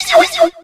infinitefusion-e18 / Audio / SE / Cries / SNOVER.ogg